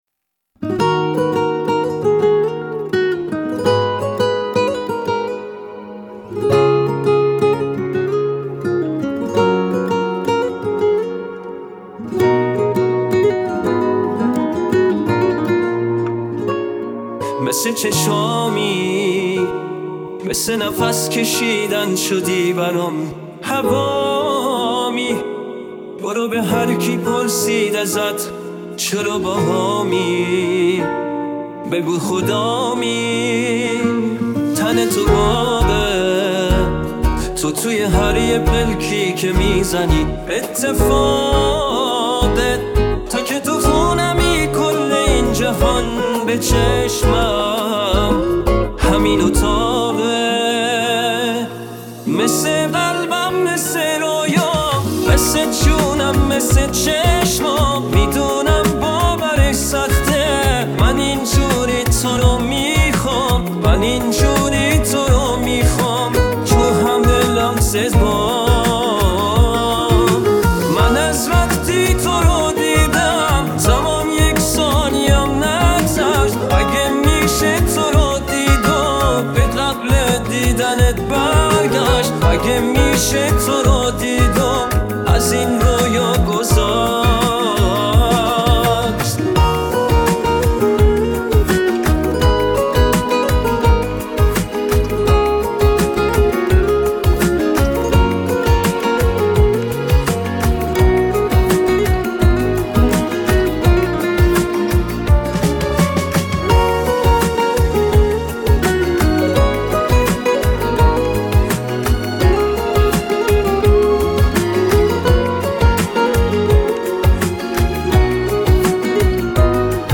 (Ai version)